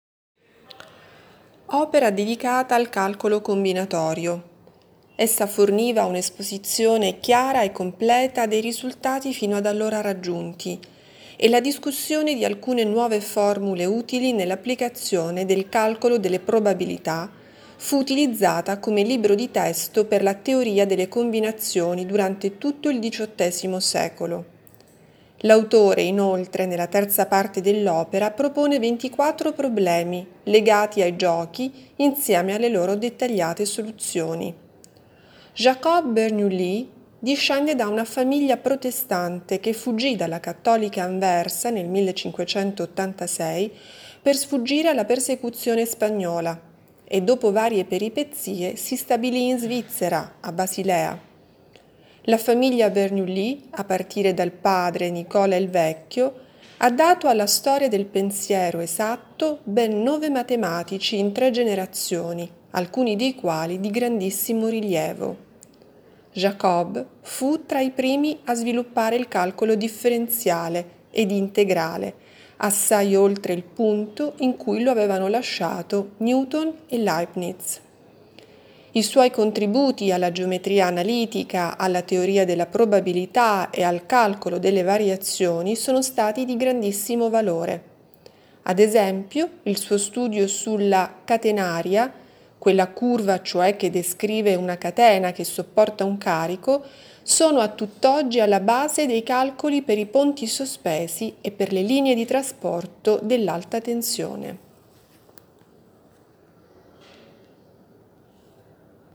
Audioguida Volumi Esposti nel 2022